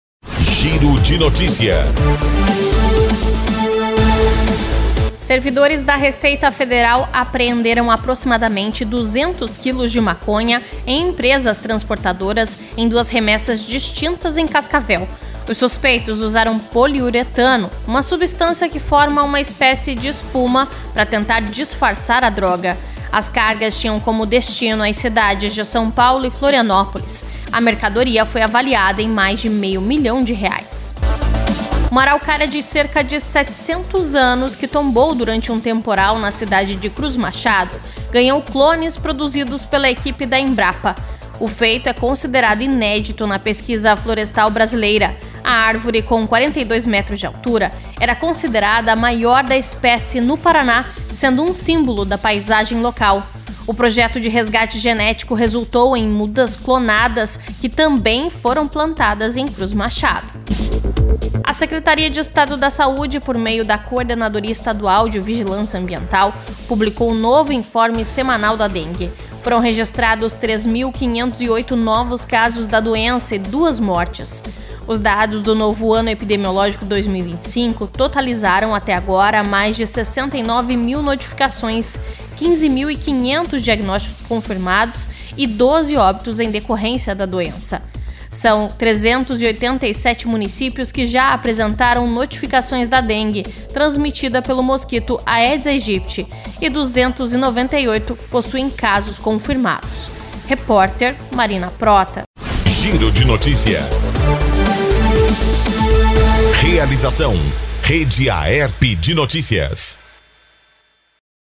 19.03-GIRO-DE-NOTICIAS.mp3